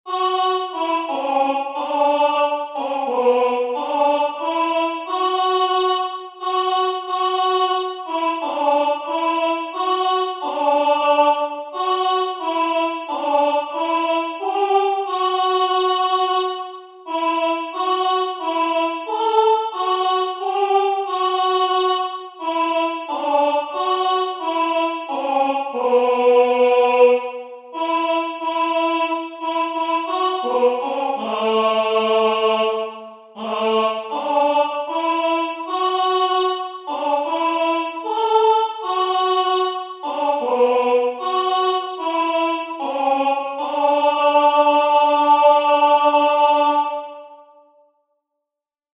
In its mournful melody, that took kindly to the cracked and weather-beaten voices of the singers, I live over again those long and lonesome nights when I lay awake, listening to the buffeting of the winds, and followed the ships on their course over the sea where it swept unchecked, wondering what the great world in which they moved might be like.